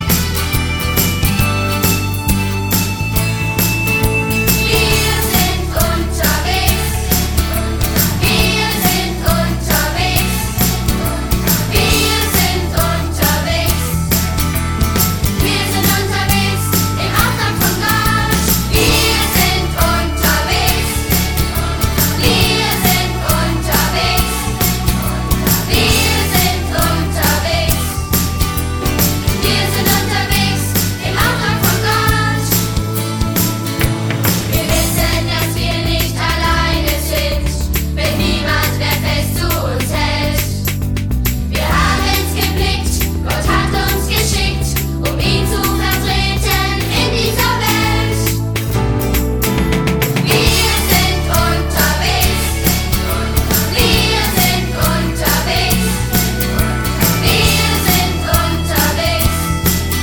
Ein Musical für die ganze Familie
Mit fetzigen Liedern und modernen Arrangements.
Kinderlieder